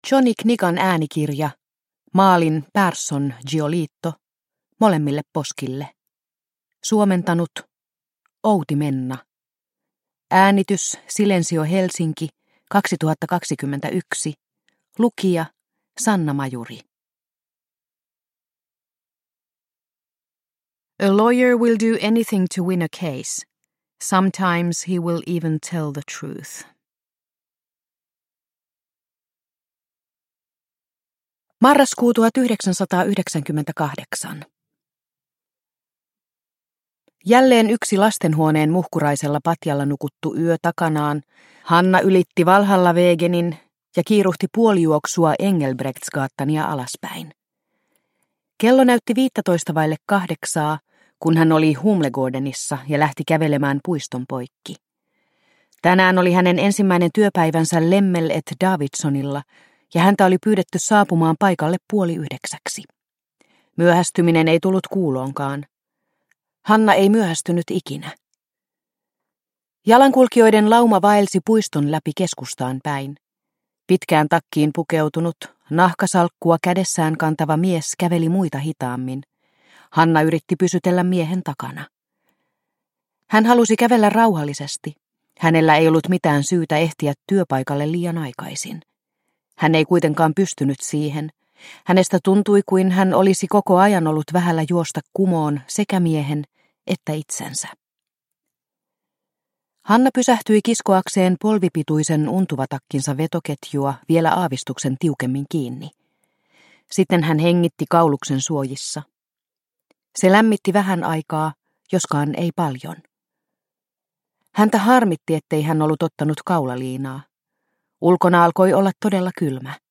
Molemmille poskille – Ljudbok – Laddas ner